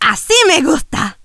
shelly_kill_01.wav